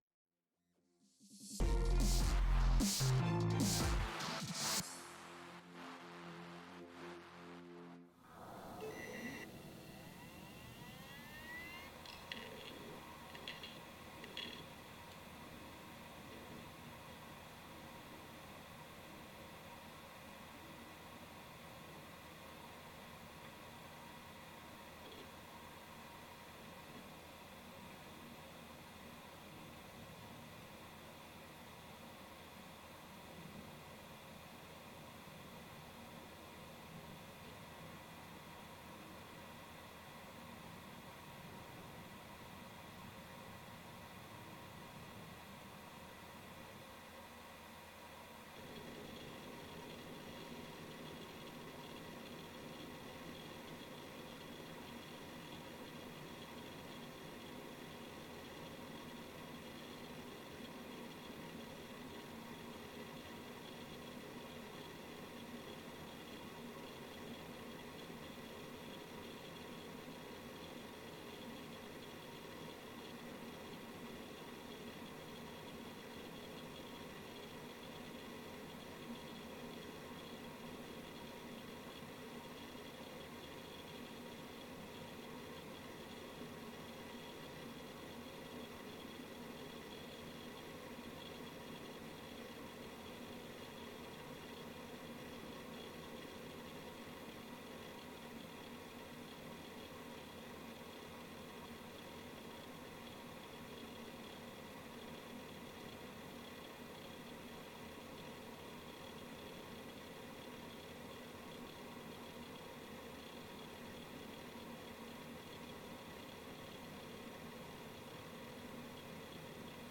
HDD noise levels table/list